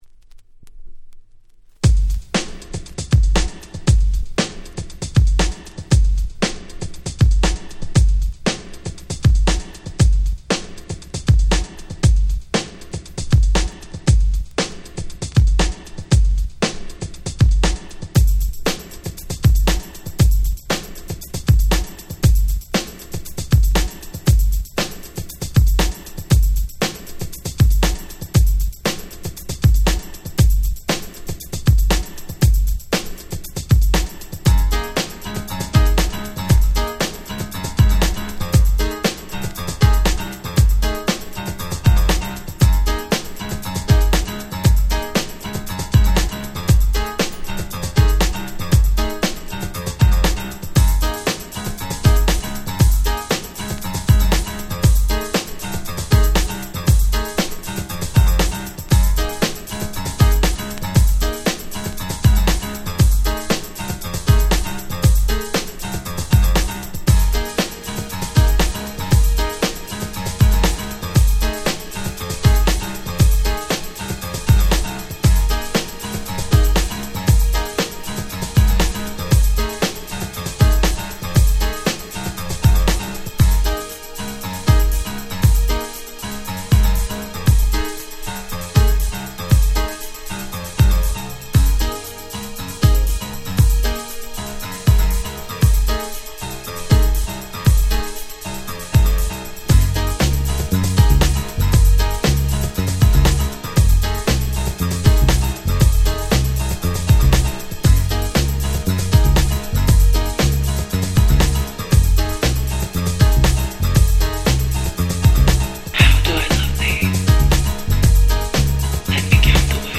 92' Very Nice Hip Hop !!
Extended Club Mix